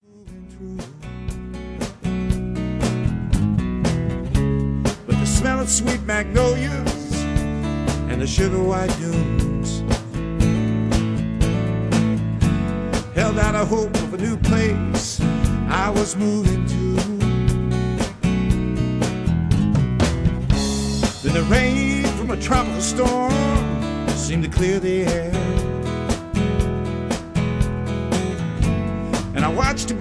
a fresh, yet experienced style to Trop- rock music